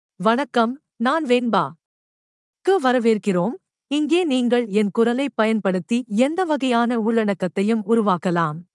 VenbaFemale Tamil AI voice
Venba is a female AI voice for Tamil (Singapore).
Voice sample
Listen to Venba's female Tamil voice.
Female
Venba delivers clear pronunciation with authentic Singapore Tamil intonation, making your content sound professionally produced.